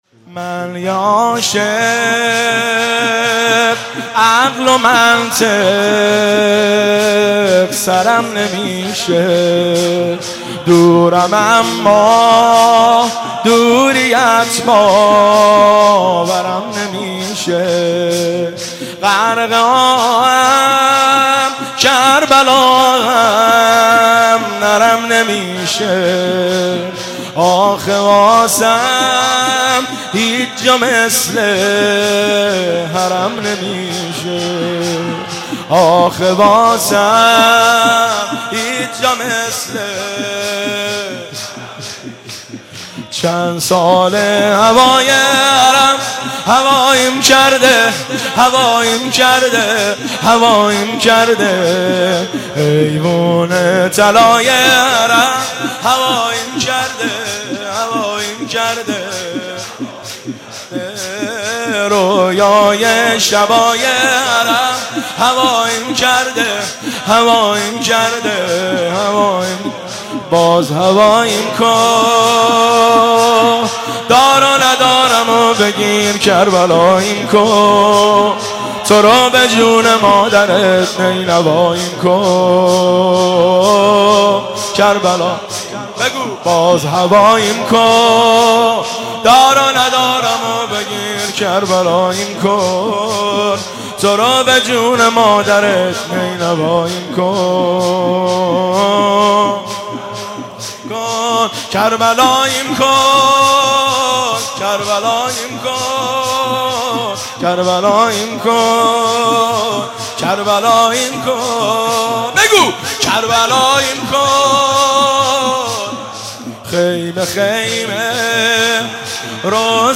مناسبت : شب بیست و دوم رمضان
قالب : شور